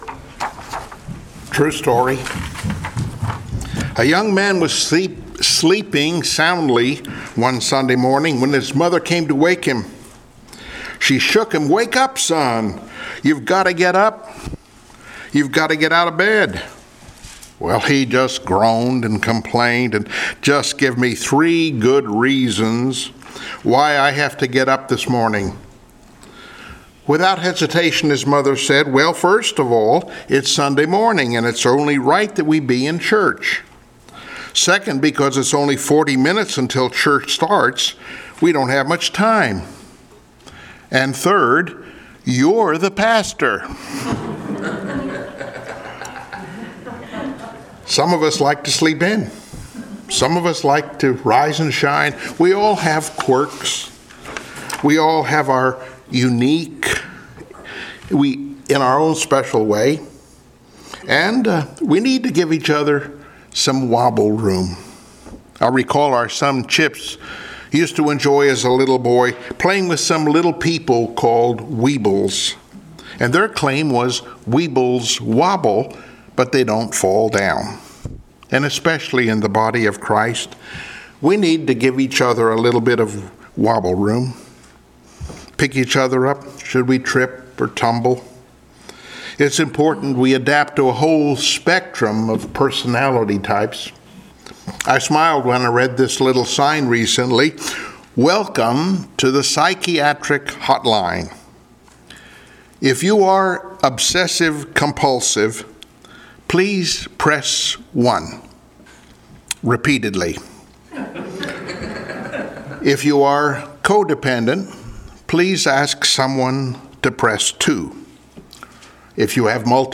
Passage: I Peter 5:1-4 Service Type: Sunday Morning Worship Download Files Notes Bulletin « ”Count Your Blessings” “From Grace to Glory” »